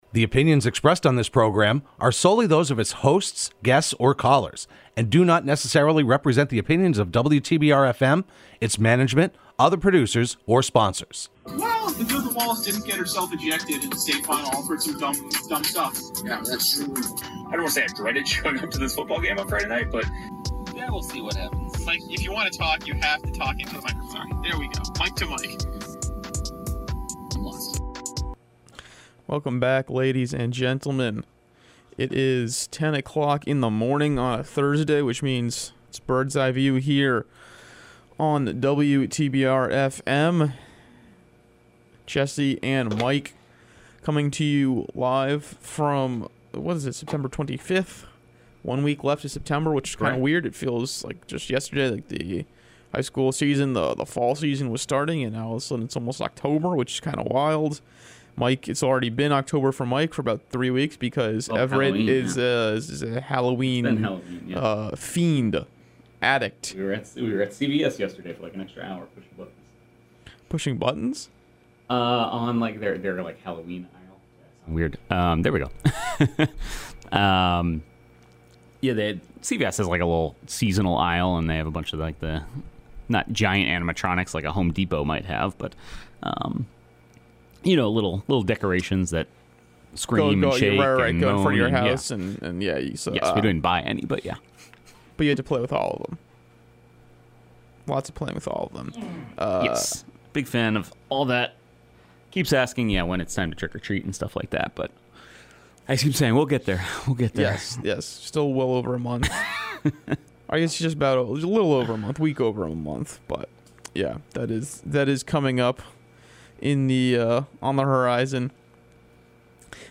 Berkshire Eagle Sports reporters catch you up on the latest in local high school sports live every Thursday morning at 10am on WTBR.